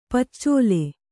♪ paccōle